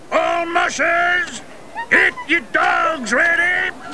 Balto_sound_Mush_dog.wav